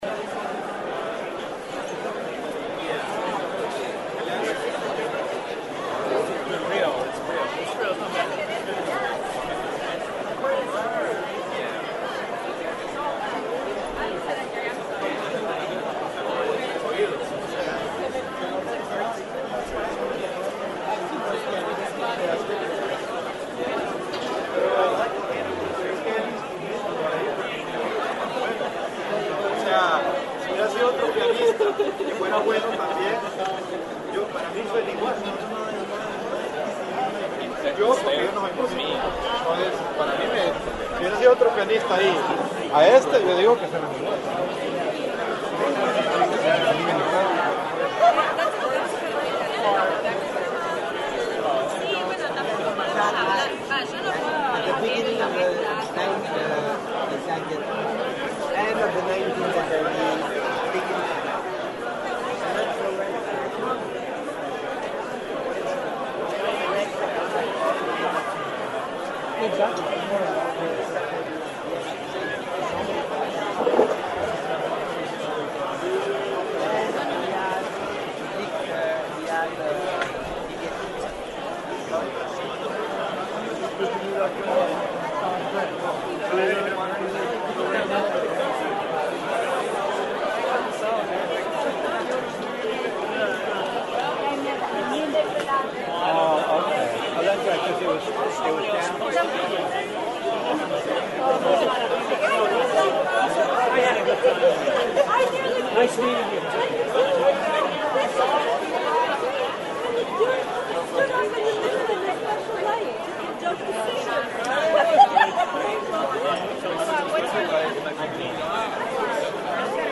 I was recording ambient conversational sounds for use in my Sound Post installation. When I was editing the recordings, I got that tingly sensation followed by a relaxed “cozy” feeling. It is actually a great sound to listen to while falling asleep.
Ambient.mp3